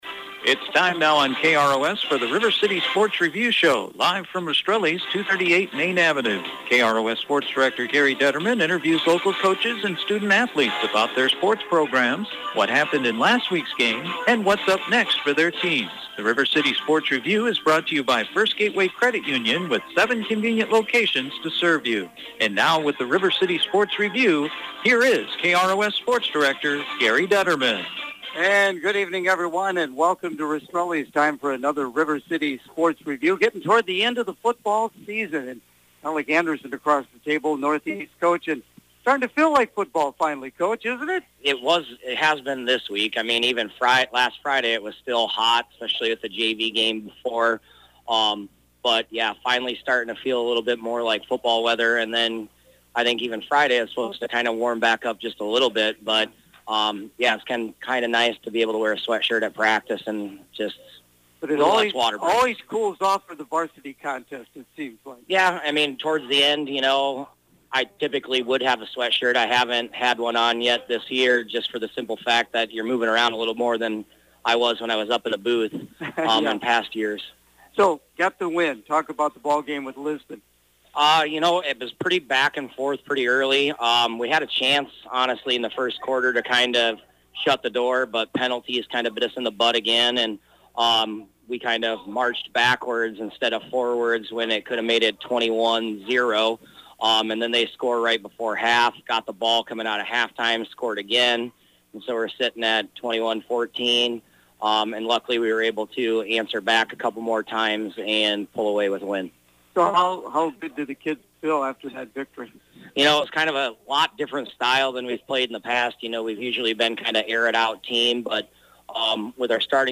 The River City Sports Review Show on Wednesday night from Rastrelli’s Restaurant